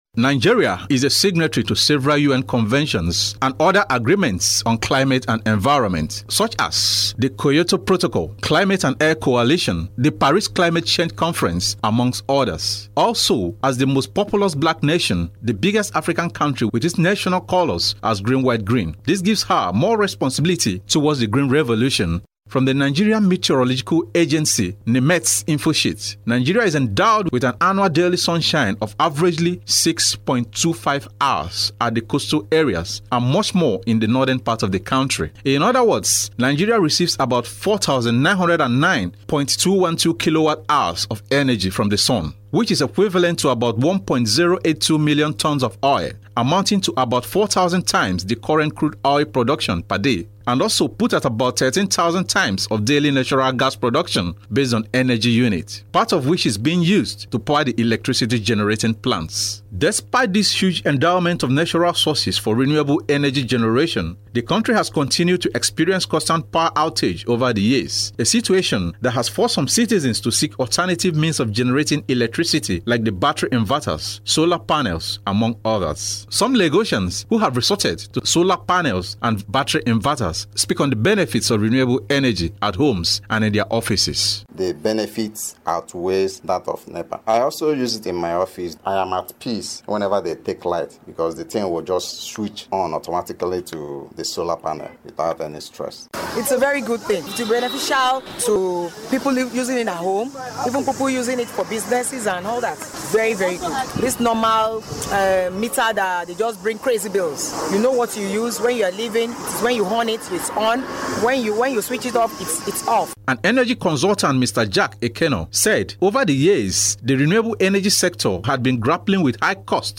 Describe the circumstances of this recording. His report was presented from our studio.